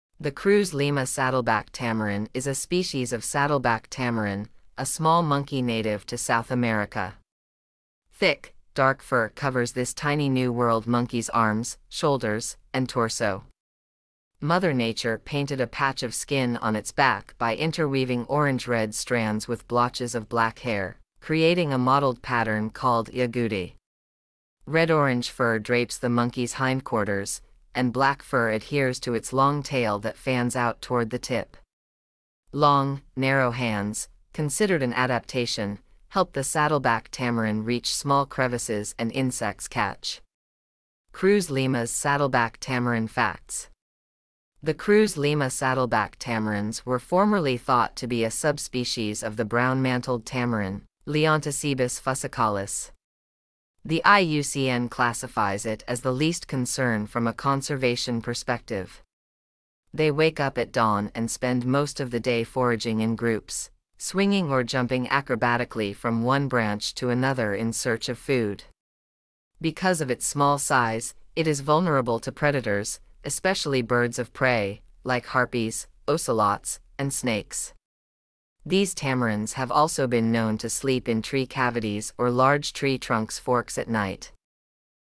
Cruz Lima’s Saddleback Tamarin
Cruz-Lima-saddleback-tamarin.mp3